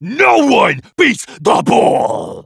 bull_lead_vo_04.wav